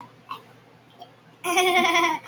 Hahahahahahaha Sound Button - Free Download & Play